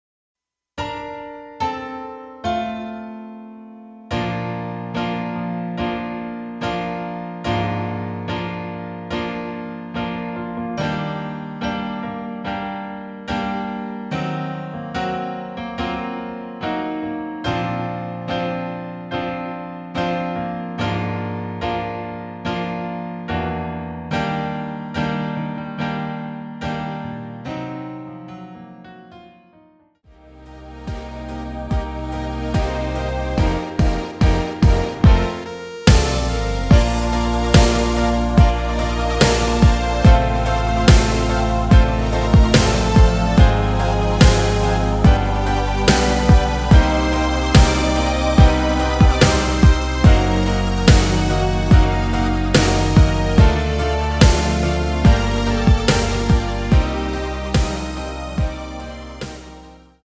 MR입니다.
Bb
앞부분30초, 뒷부분30초씩 편집해서 올려 드리고 있습니다.
중간에 음이 끈어지고 다시 나오는 이유는